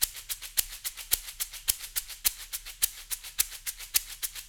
CLB SHAKER.wav